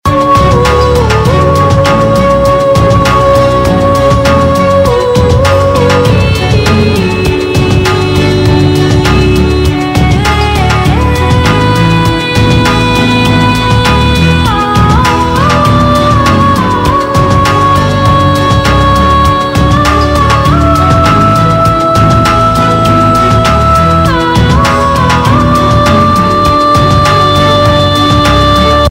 Instrumental MP3 Ringtones